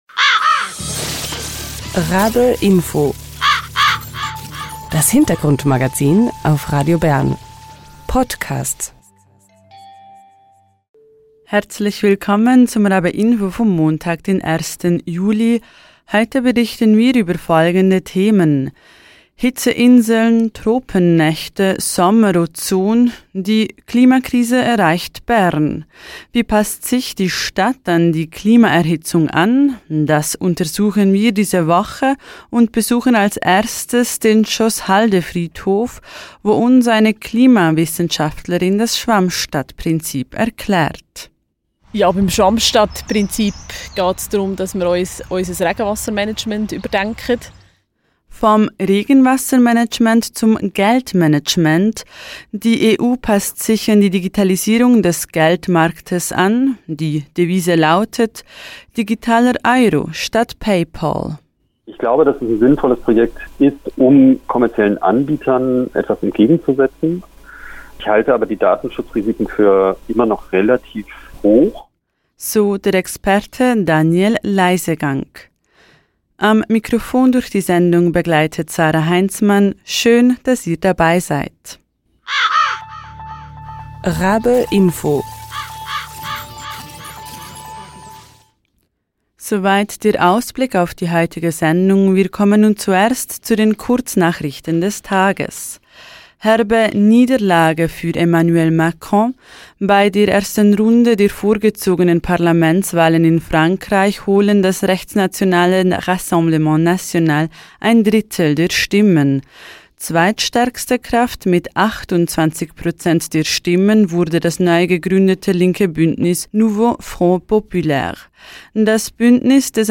Wie passt sich die Stadt an die Klimaerhitzung an? Das untersuchen wir diese Woche und besuchen als erstes den Schlosshaldefriedhof, wo uns eine Umweltwissenschaftlerin das Schwammstadtprinzip erklärt. Vom Regenwassermanagement zum Geldmanagement: Die EU passt sich an die Digitalisierung des Geldmarktes an.